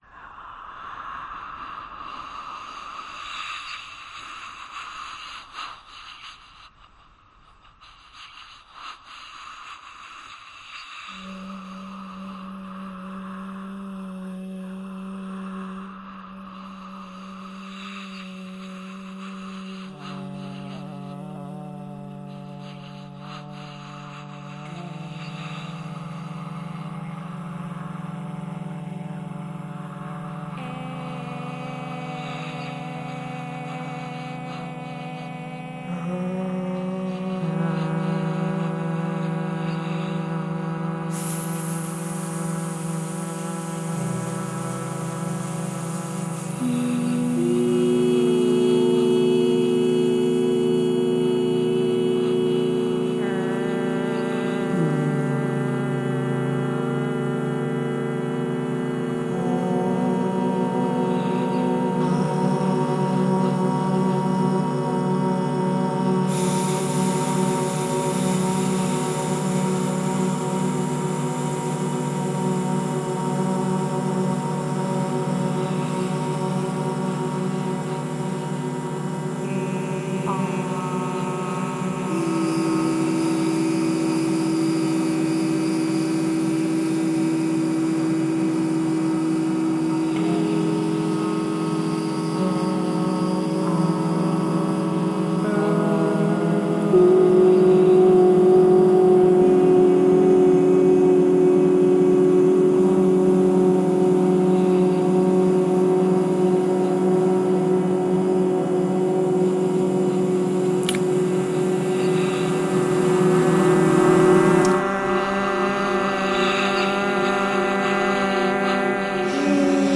Video and sound installation
Phonetic Singers